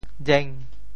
“礽”字用潮州话怎么说？
潮州发音 潮州 rêng5
jeng5.mp3